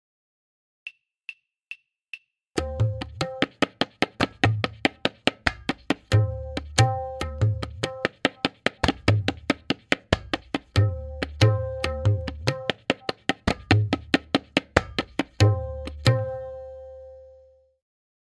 Note: The following forms are all played in dugun (2:1).
Sam-to-Sam Bedam Tihai
M8.5-Bedam-Click.mp3